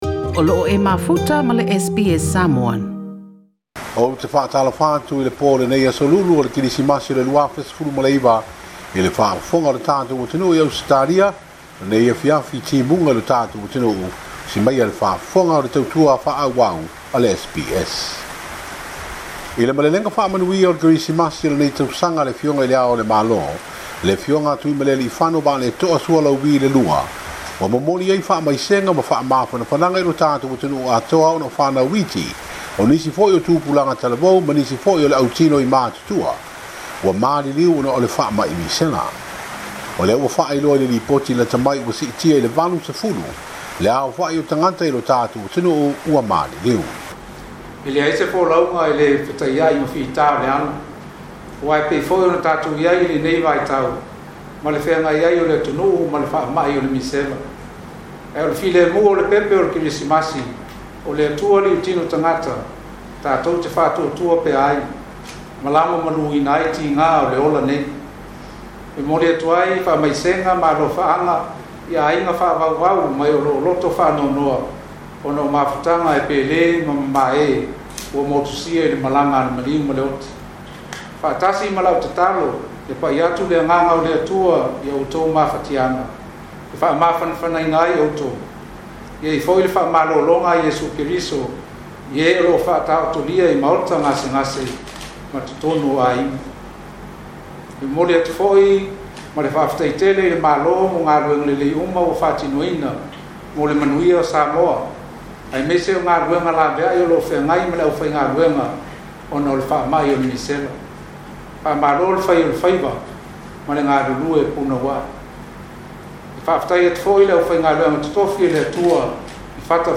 Se lipoti lata mai mai Samoa